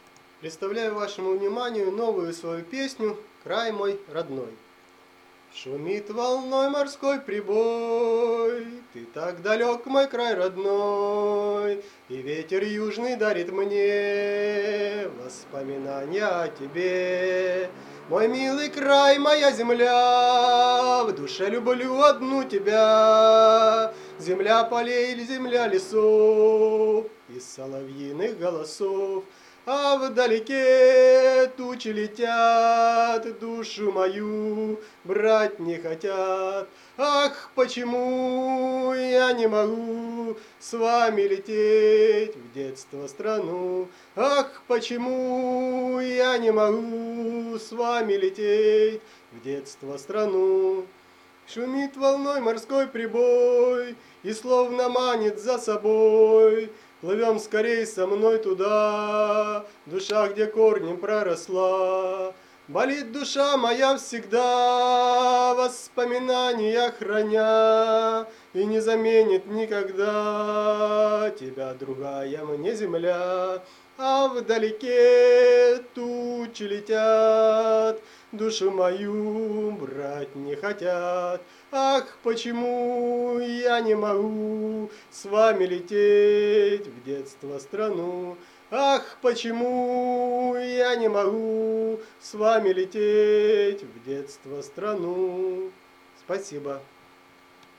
Рубрика: Поезія, Авторська пісня
Ось які мають бути почуття до рідного краю, красиво описали, мелодійно з грустинкою 12 give_rose friends
Гарні у Вас пісні і дуже мелодійні.